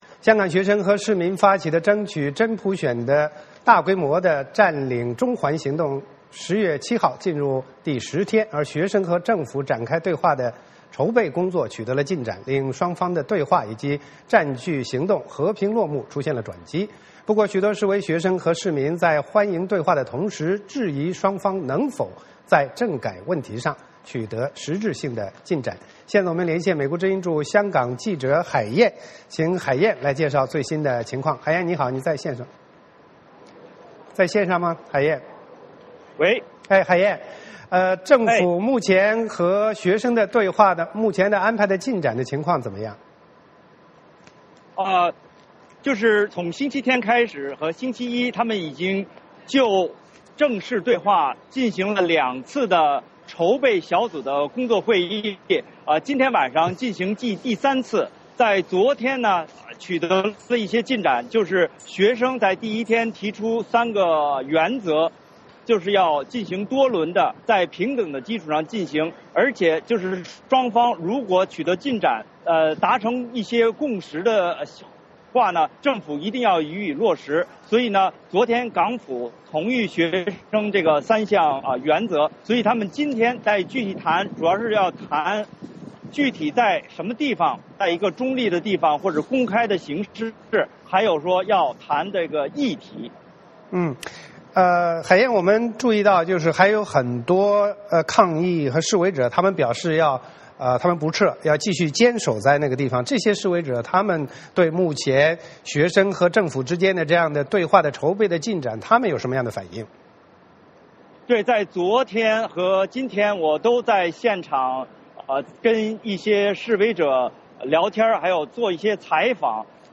VOA连线：香港学生与政府对话筹备有进展